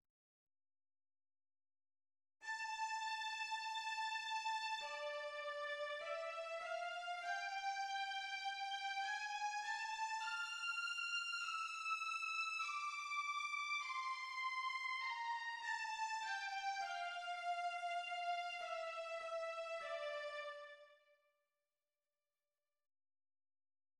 Nowak places all markings of Andante for this B major movement in parentheses:
The second theme is introduced by the first violins, accompanied by the second violins and violas: